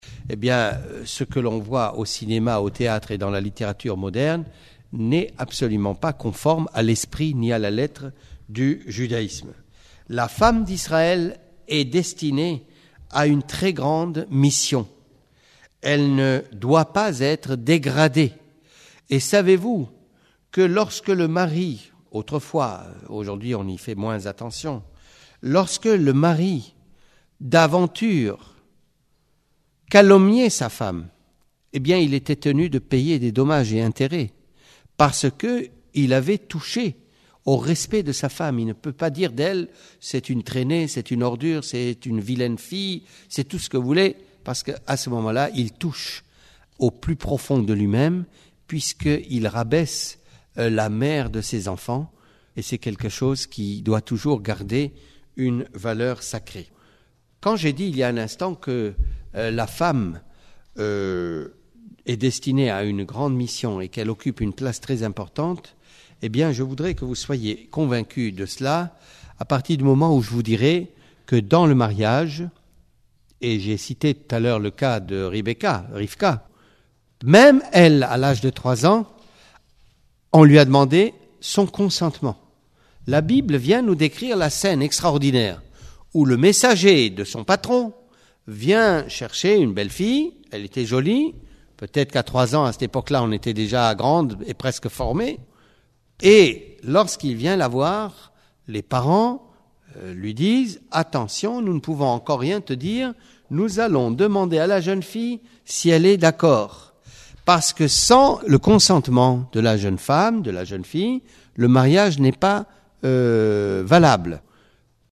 01:31:31 Exposé donné par le Grand Rabin de Paris Rav Alain Avraham GOLDMANN zatsal au consistoire de Paris dans le cadre de ses activités auprès des candidats au mariage.